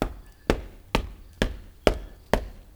FOOTSTOMP1-R.wav